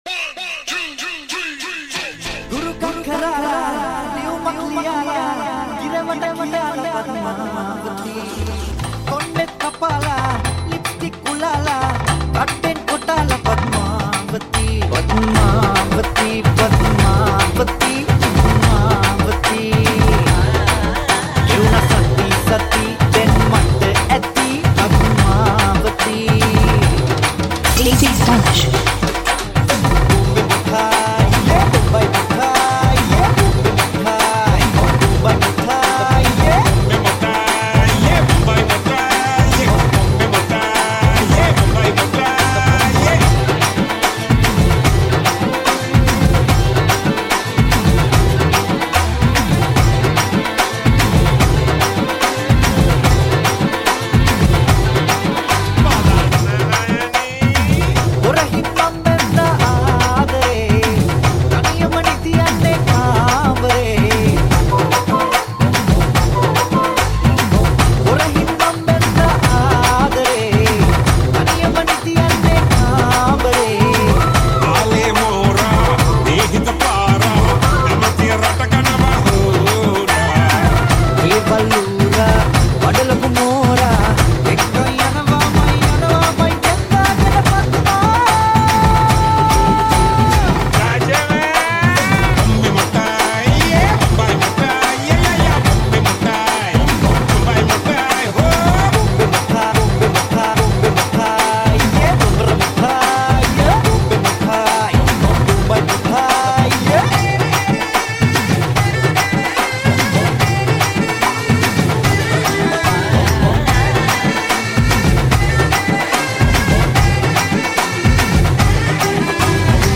High quality Sri Lankan remix MP3 (2.9).